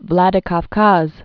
(vlădĭ-käfkäz, vlə-dyĭ-kəf-käs)